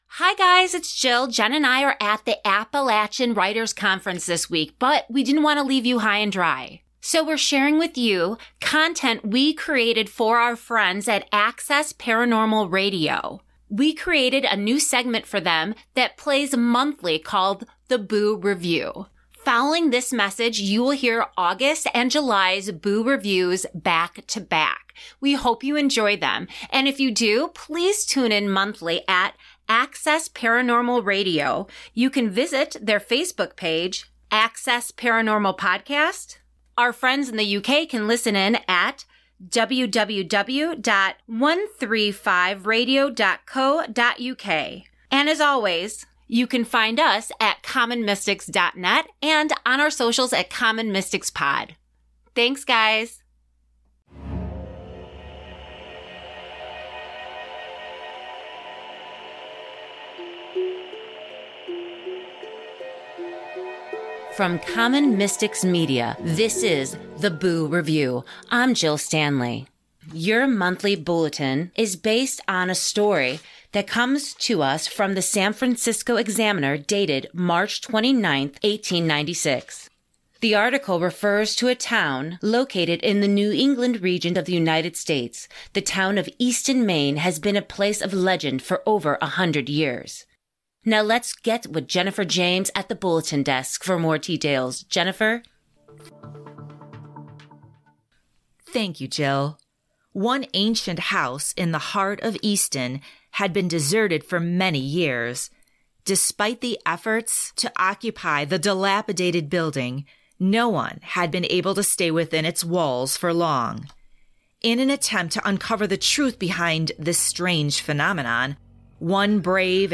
Told in a faux-newsy style, these short, richly atmospheric clips will transport you back in time and delight you with "true" spooky tales. Episode 1 of The Boo Review comes to you from the San Francisco Examiner dated Mar. 29, 1896 and delivers two chilling tales from the haunted town of Easton, Maine. Episode 2 details one reverend's showdown with evil in Smyrna, Delaware as described in the Smyrna Times on Jan. 6, 1881.